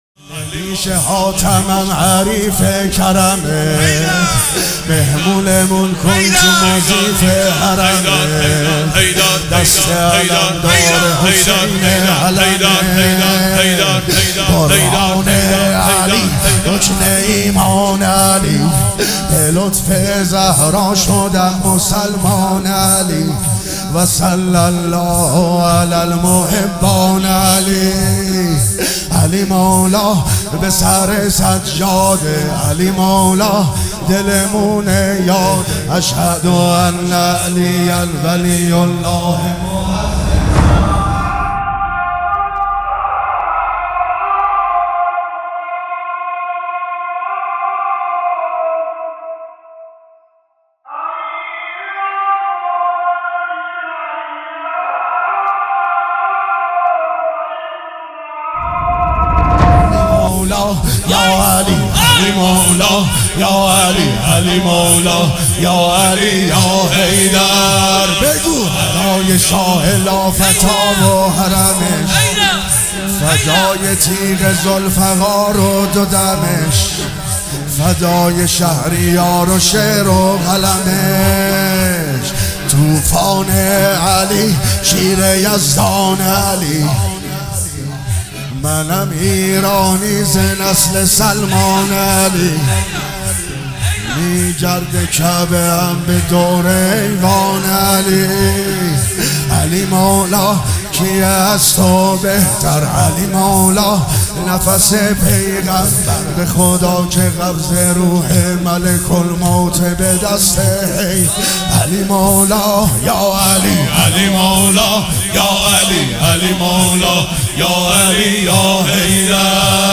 نمیشه حاتمم حریف کرمت عبدالرضا هلالی | اجتماع عزاداران امیرالمومنین | پلان 3